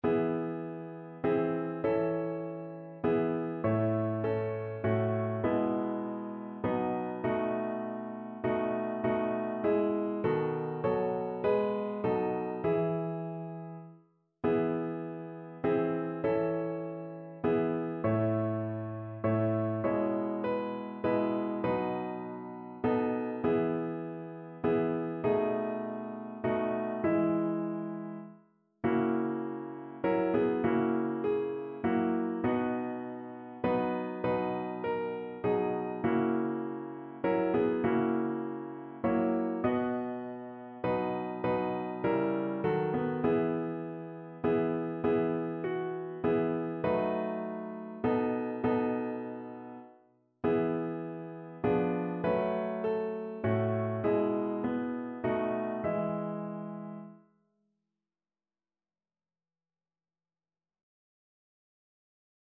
Notensatz 1 (4 Stimmen gemischt)
• gemischter Chor [MP3] 975 KB Download